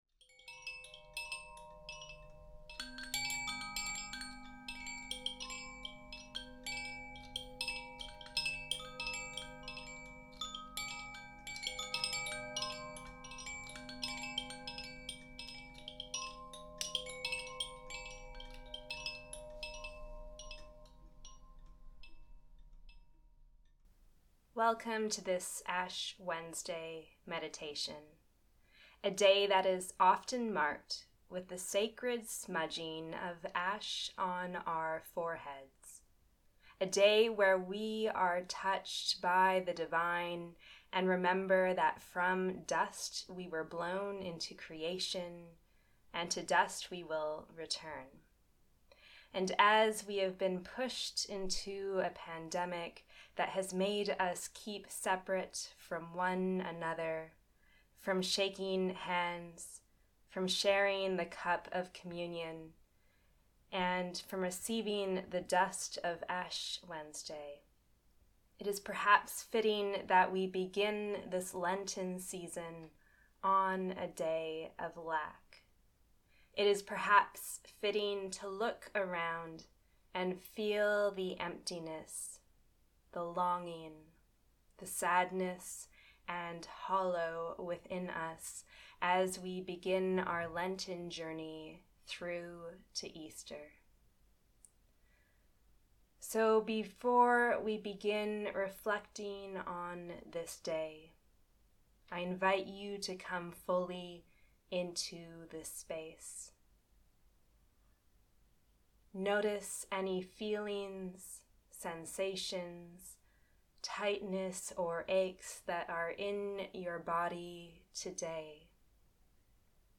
Ash Wednesday Meditation